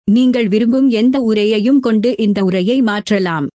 Professionelle Sprachausgabe zum Vorlesen und Vertonen beliebiger Texte
Professionelle, natürlich klingende männliche und weibliche Stimmen in vielen Sprachen, die kaum mehr von einem menschlichen Sprecher zu unterscheiden sind.